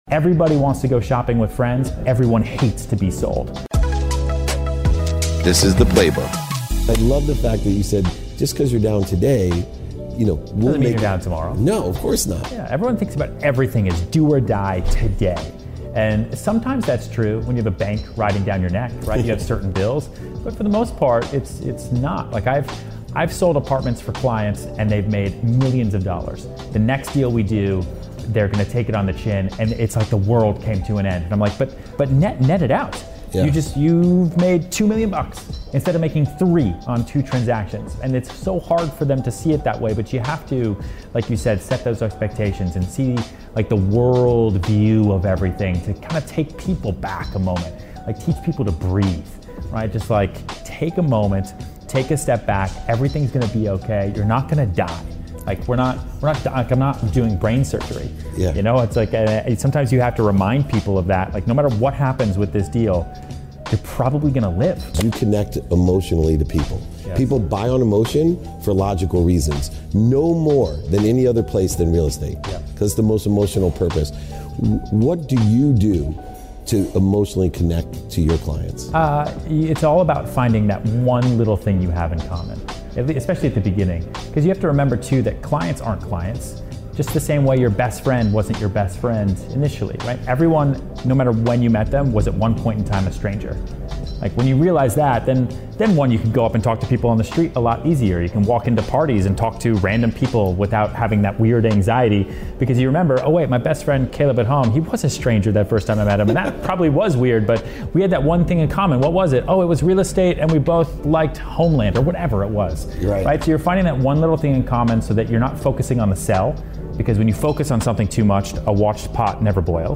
Today's episode features a conversation with Ryan Serhant, an acclaimed real estate broker and television personality, as we explore the intricacies of sales and client relationships in real estate. Ryan shares insights on navigating challenges, like transforming a 'no' into a potential opportunity and forging emotional connections with clients. We discuss the critical role of finding commonality, as every client was once an unfamiliar face, and how focusing on shared interests rather than the sale can build stronger bonds.